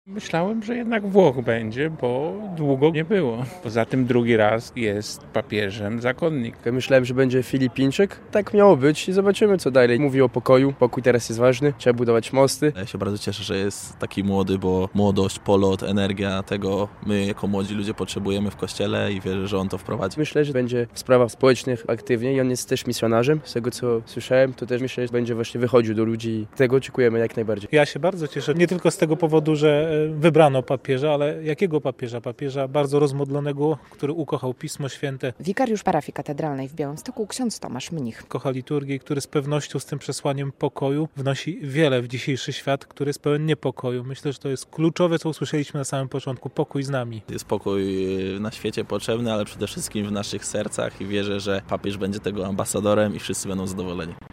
Zaskoczenie, radość i nadzieja - to dominujące reakcje po wyborze nowego papieża - relacja